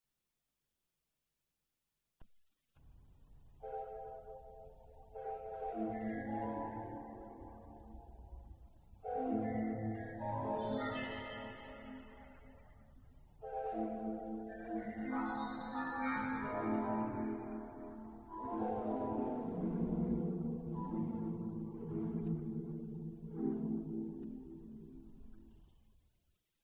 Improvisation